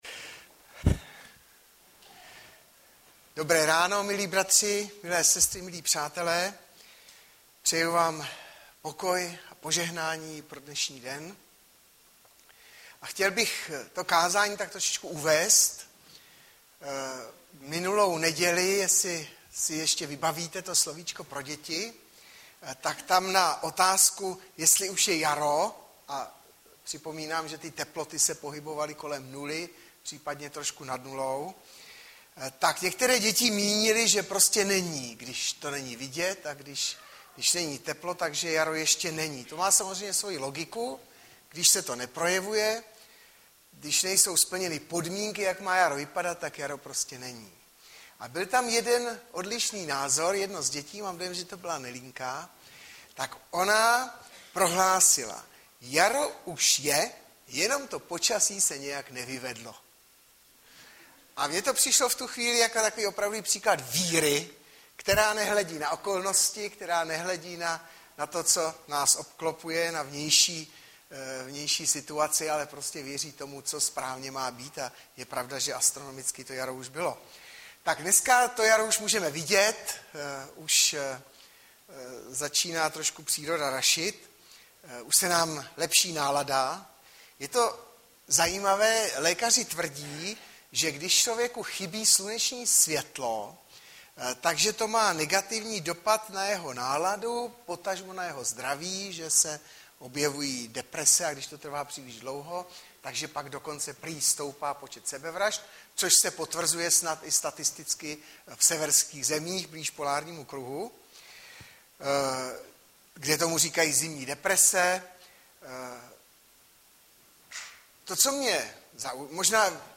Hlavní nabídka Kázání Chvály Kalendář Knihovna Kontakt Pro přihlášené O nás Partneři Zpravodaj Přihlásit se Zavřít Jméno Heslo Pamatuj si mě  14.04.2013 - KÁZÁNÍ PRO UNAVENÉ - Iz 40,25-31 Audiozáznam kázání si můžete také uložit do PC na tomto odkazu.